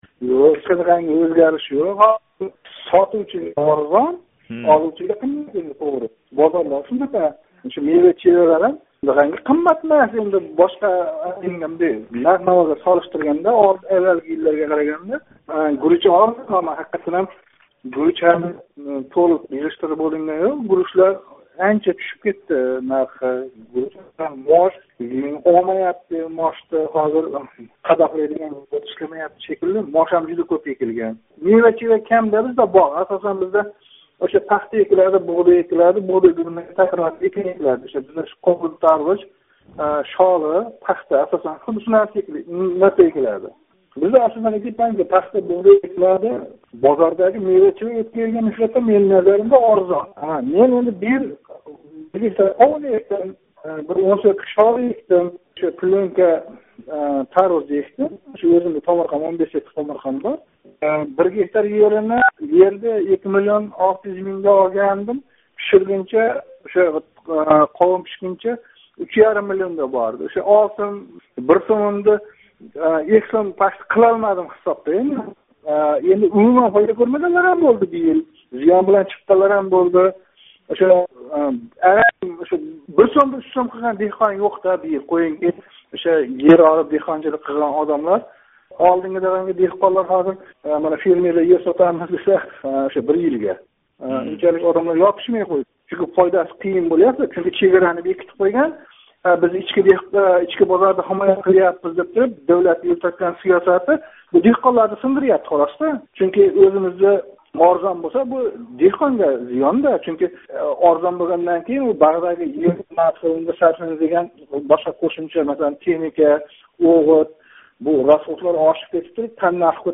Фермер